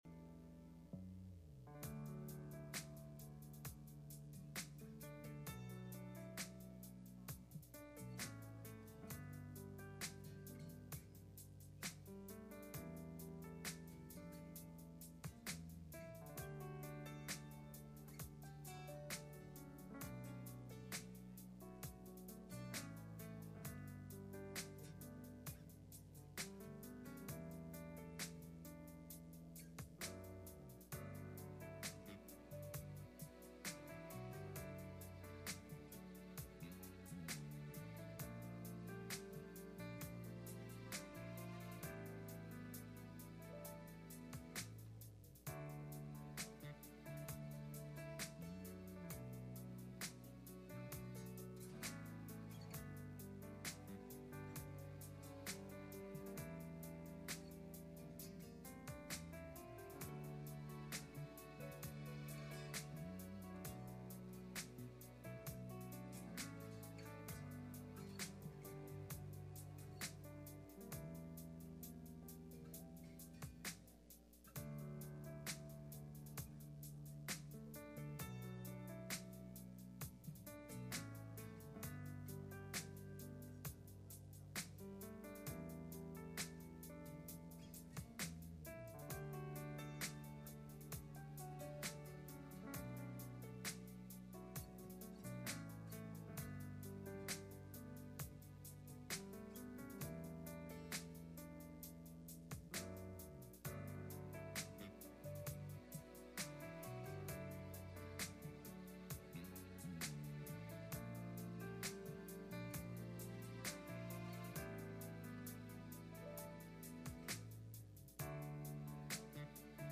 2025 Bible Study Preacher
Message Service Type: Midweek Meeting https